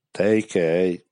10 frasi in dialetto